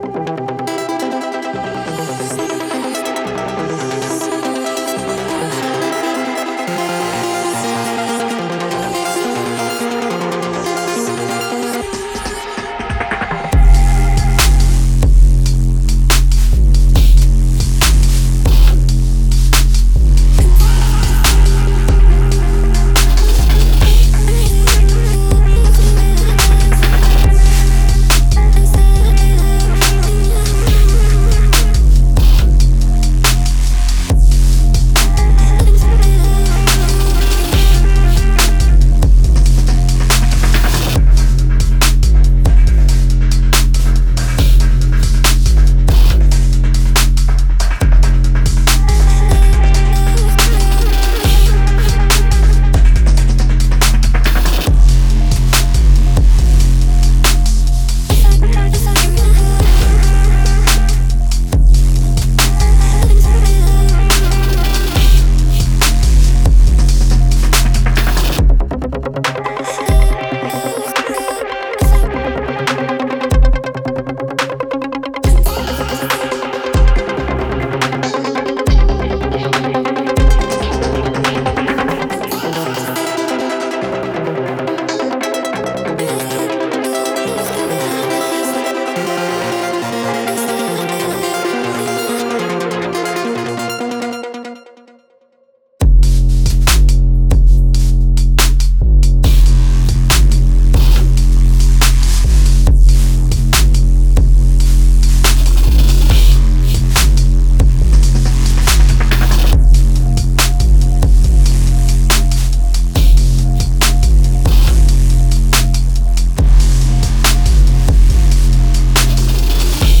キャッチーなフックも満載のポスト・ベース最前線です。